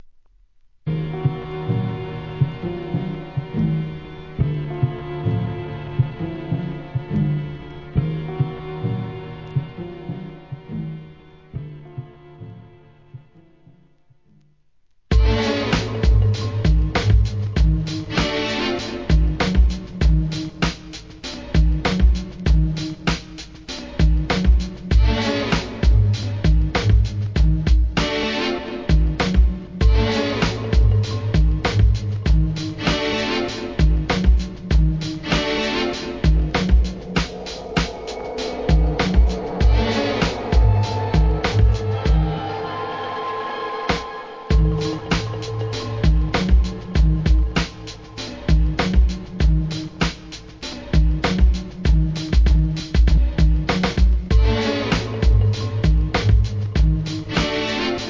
HIP HOP/R&B
らしさ満点なスリリングで危険なBEAT満載です!!